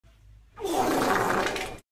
Diarrhea Fart Noise Bouton sonore